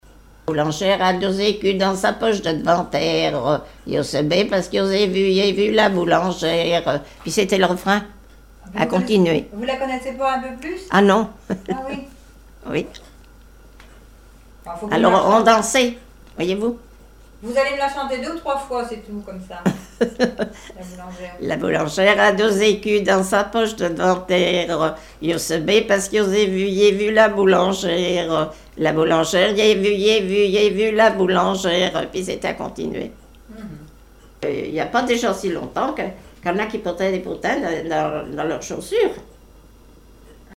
Rondes enfantines à baisers ou mariages
danse : ronde : boulangère
chansons et témoignages
Pièce musicale inédite